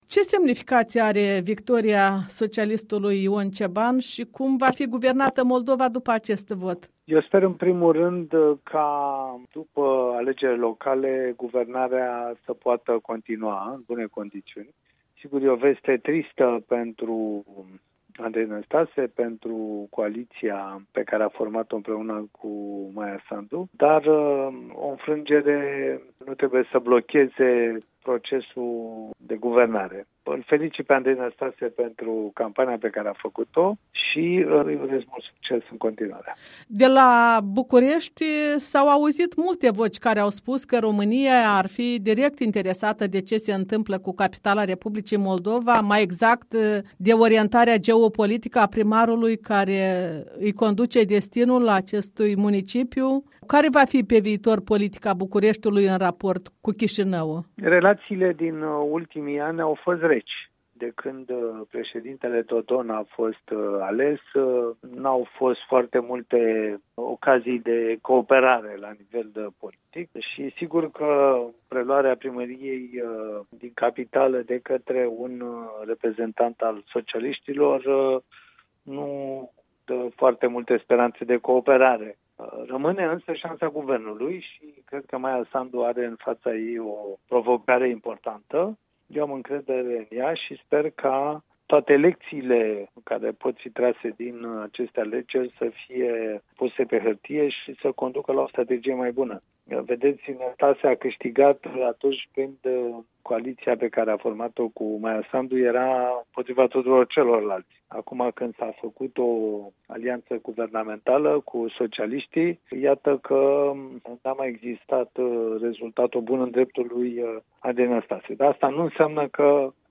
Interviu cu Cristian Preda